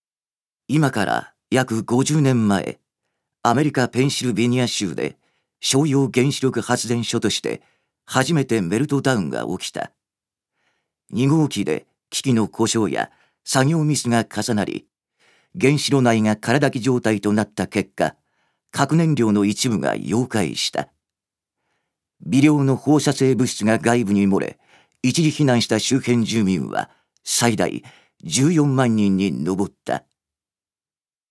預かり：男性
ナレーション１